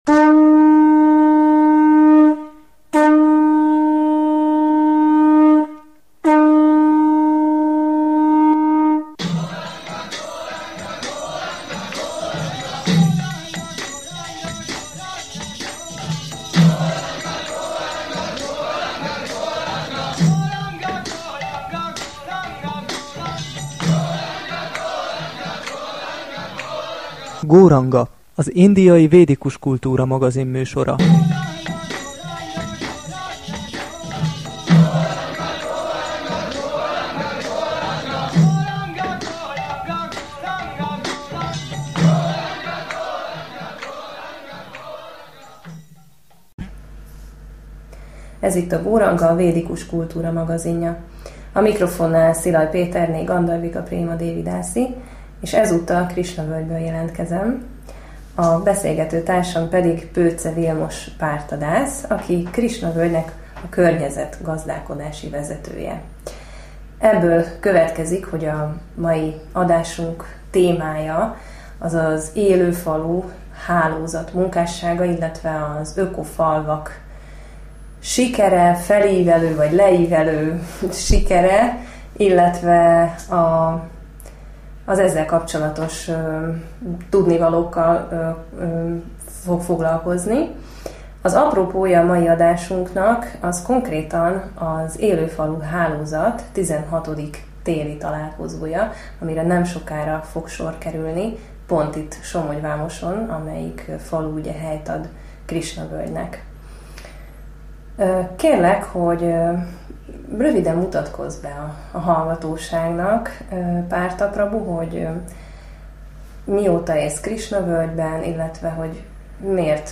Milyen lehet ökofaluban élni? – rádiós beszélgetés - Öko-völgy Alapítvány